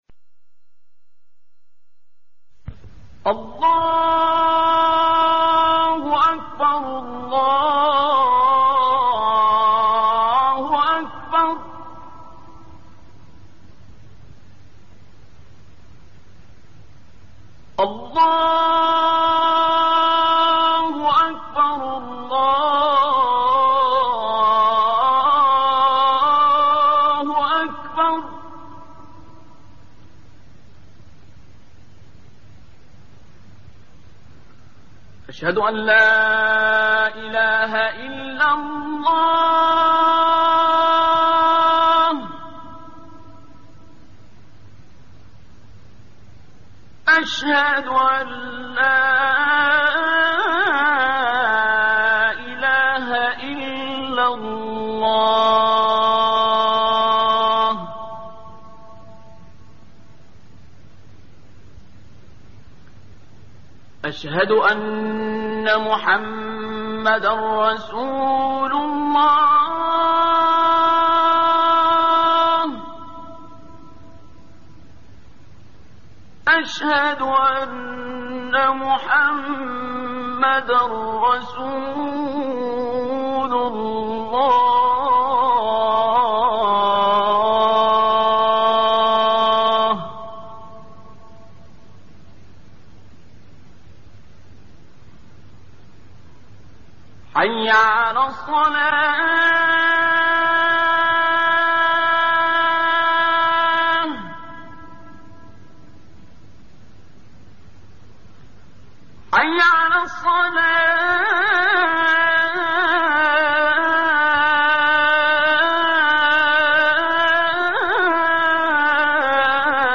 صوت زیبای عبدالباسط
azan-abdolbasit.mp3